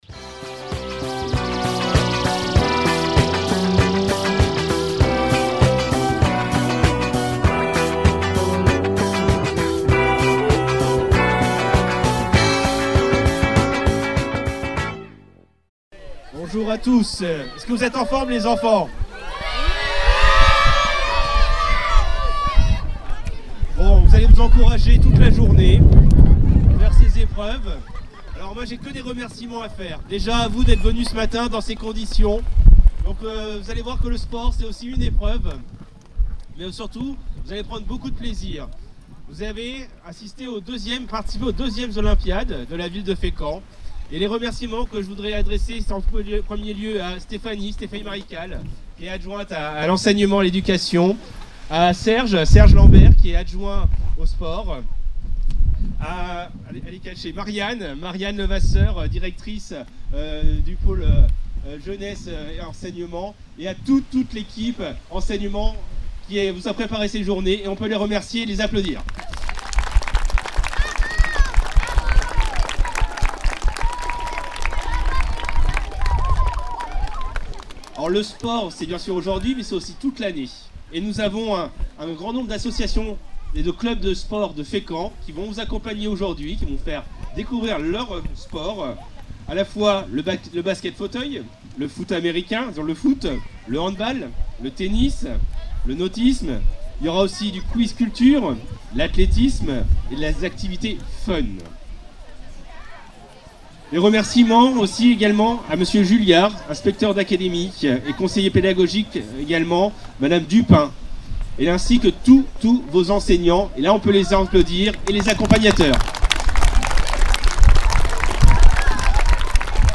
Olympiades des écoles 2025 - Cérémonie d'ouverture du 11.06.2025